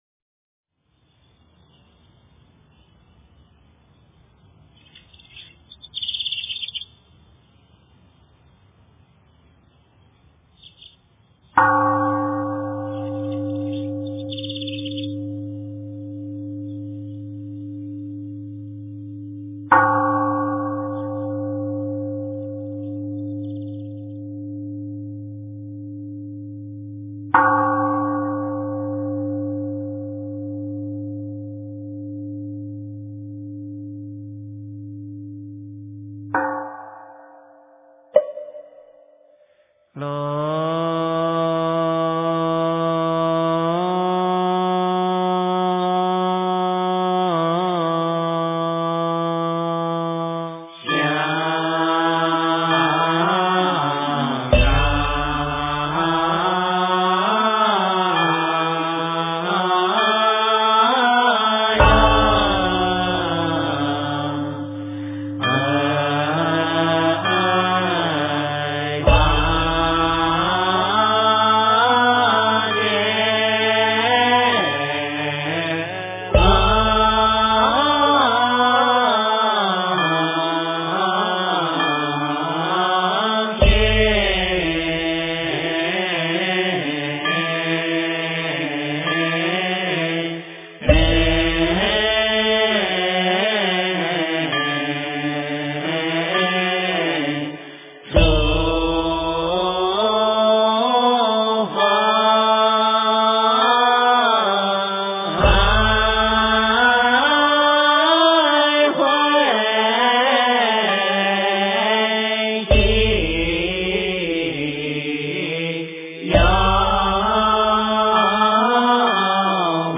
诵经
佛音 诵经 佛教音乐 返回列表 上一篇： 杂阿含经卷二 下一篇： 金刚经 第二十一部至第二十九部 相关文章 六字大明咒--佛教音乐 六字大明咒--佛教音乐...